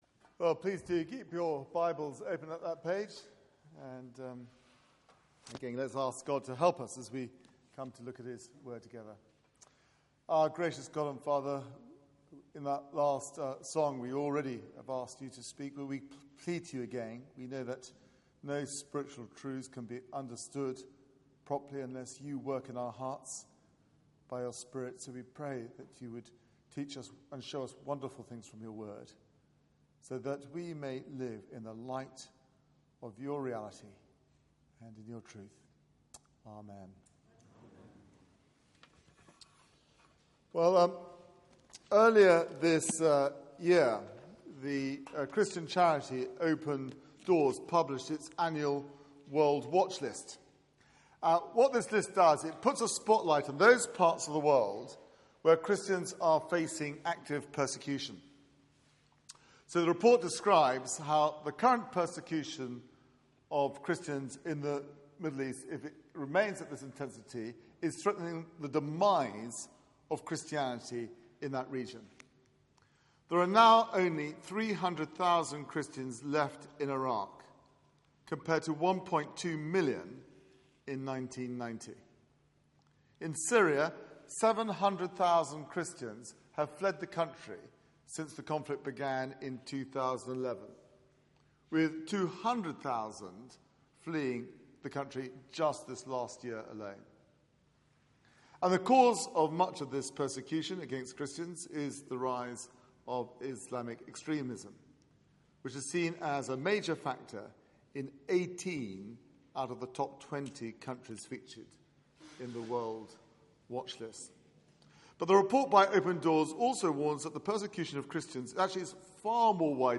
Passage: Exodus 2:1-10 Service Type: Weekly Service at 4pm